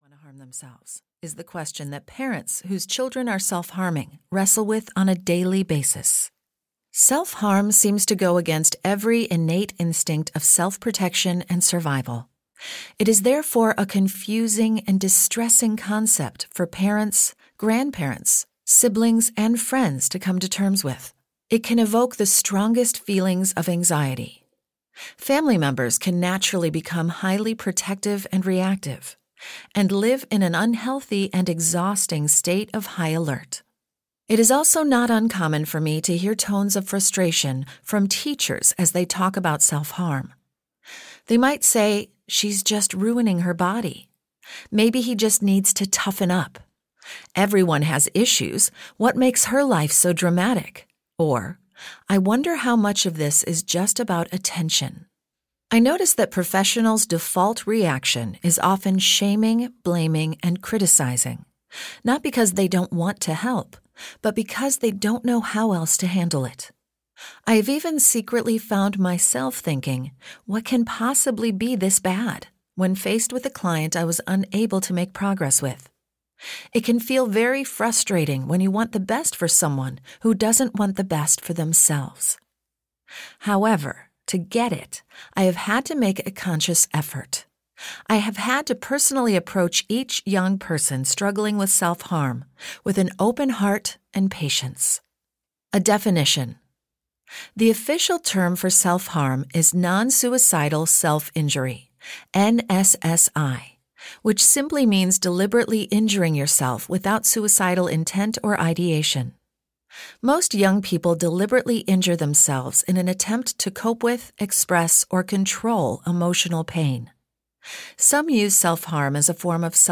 Audio knihaSelf Harm: Why Teens Do It And What Parents Can Do To Help (EN)
Ukázka z knihy